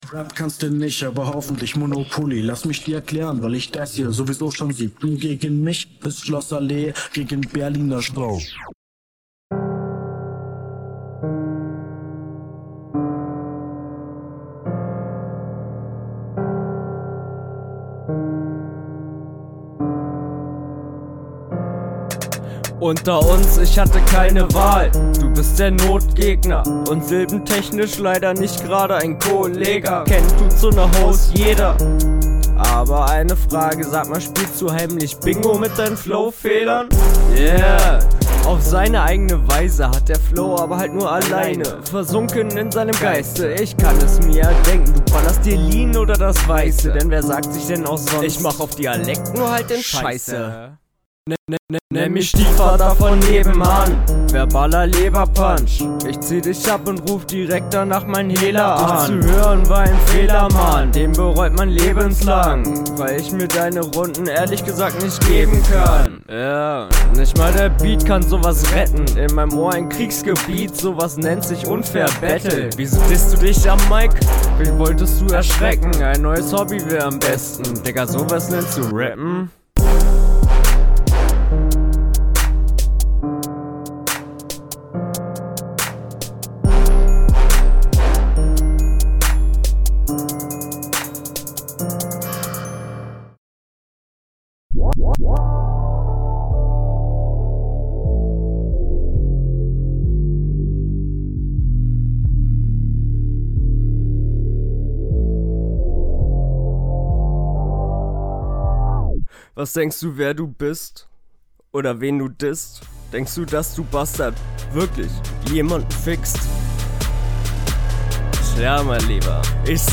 Find den Flow im ersten Teil deutlich besser als in der RR1. aber was hat …
Auf den Beats kommst du viel viel besser als auf dem anderen.